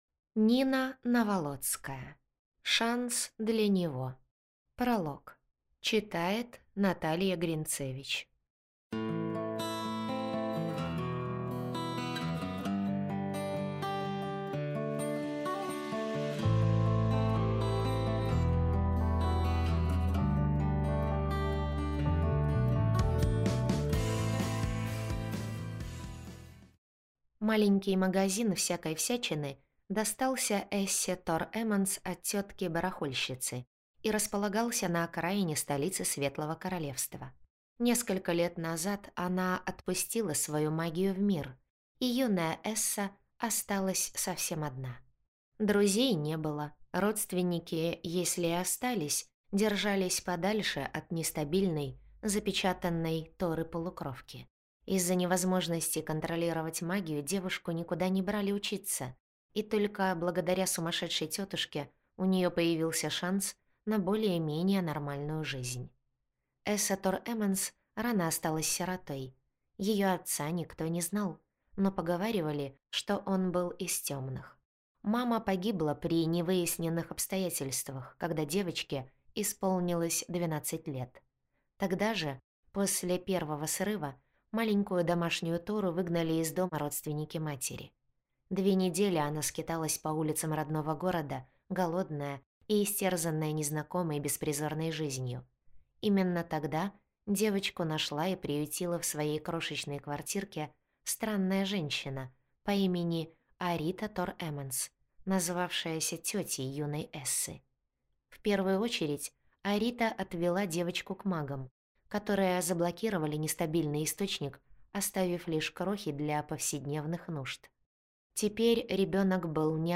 Аудиокнига Шанс для него. Бонус | Библиотека аудиокниг